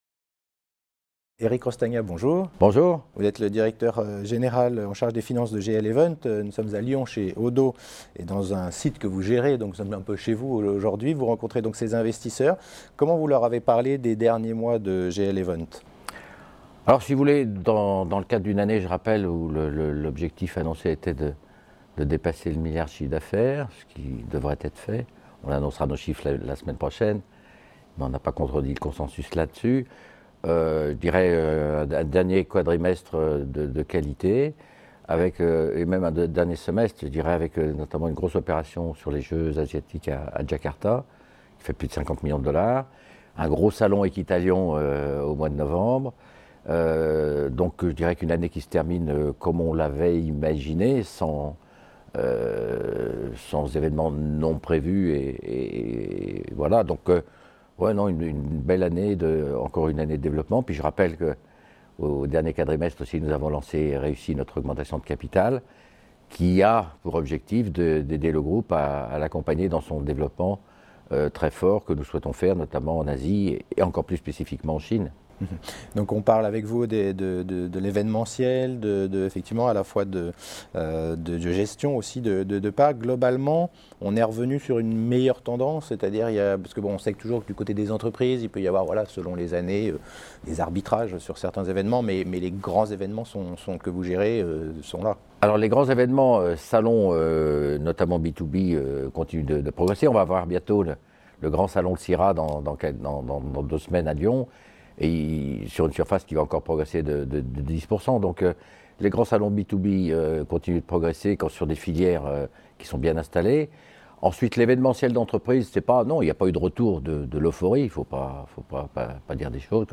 La Web Tv a rencontré les dirigeants au Oddo Forum qui s’est tenu à Lyon le 10 et le 11 janvier.